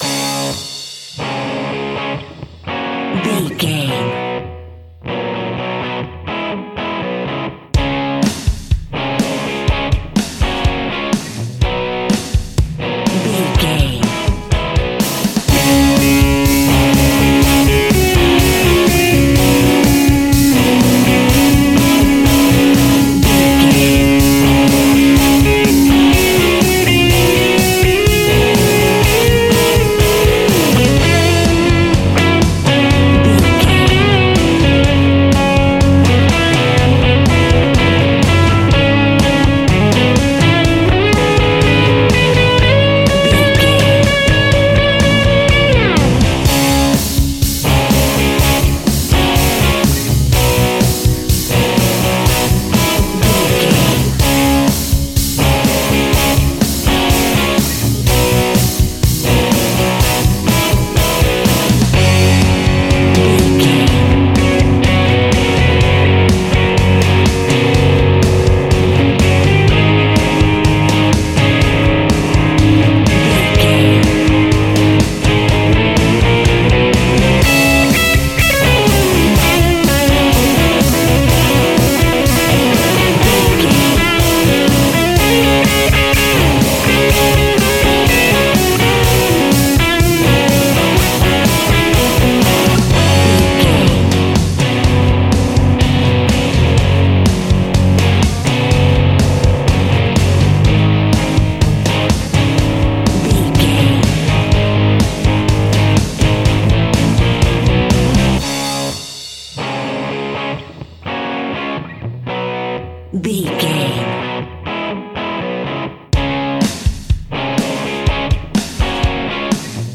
Uplifting
Ionian/Major
D♭
hard rock
heavy metal
blues rock
distortion
rock guitars
Rock Bass
Rock Drums
heavy drums
distorted guitars
hammond organ